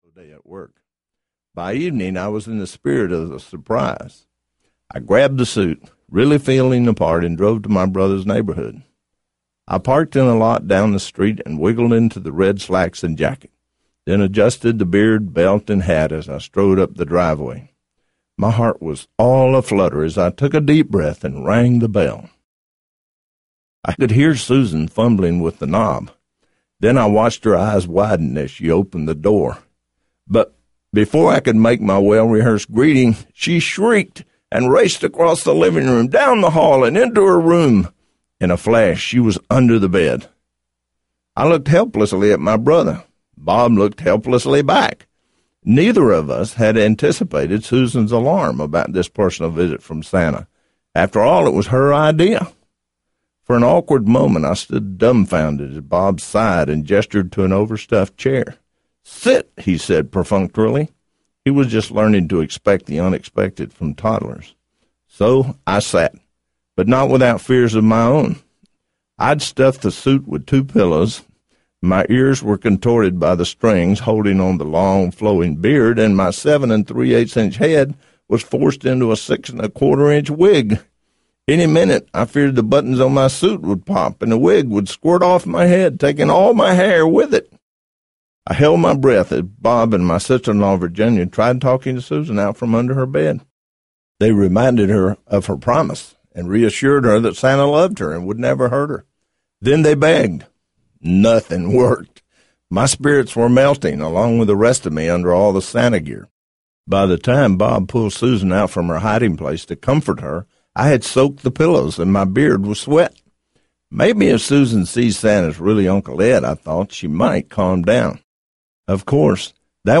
The Red Suit Diaries Audiobook
Narrator
3 Hrs. – Abridged